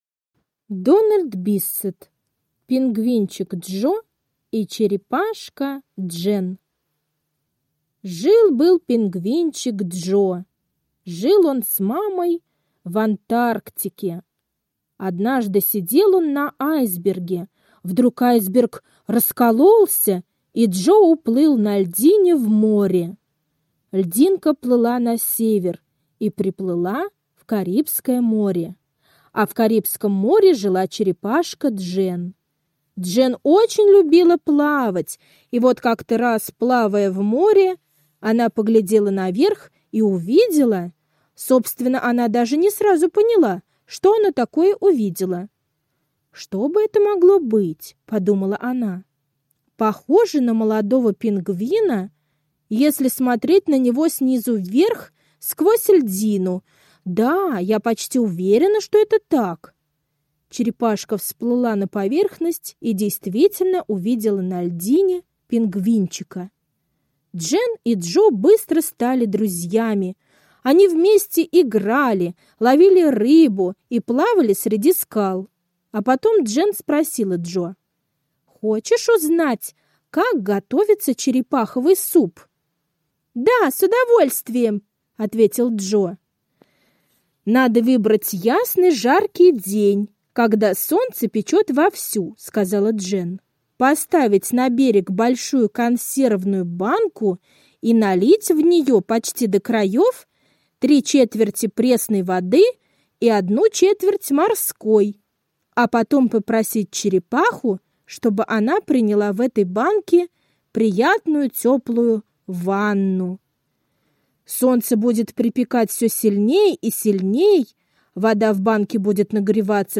Пингвинчик Джо и Черепашка Джейн — аудиосказка Биссет Д. Сказка о невероятных приключениях пингвинчика Джо.